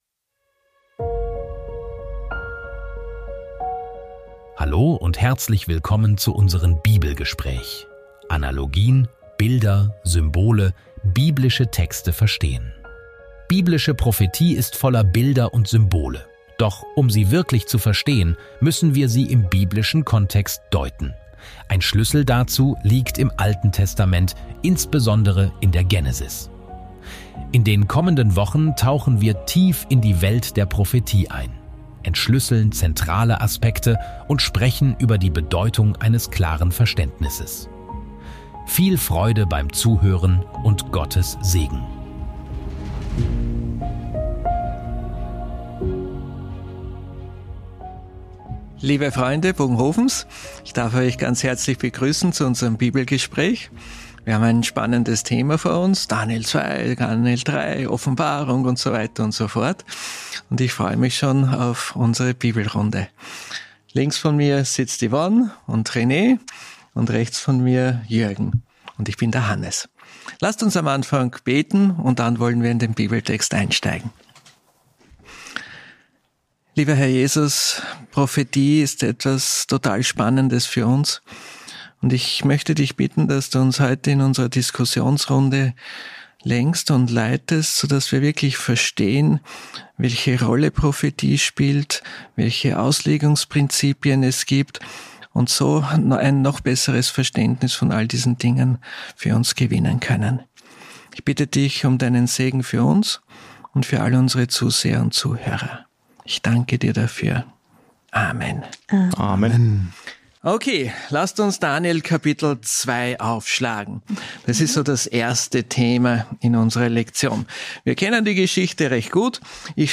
Hier hören Sie das Sabbatschulgespräch aus Bogenhofen zur Weltfeldausgabe der Lektion der Generalkonferenz der Siebenten-Tags-Adventisten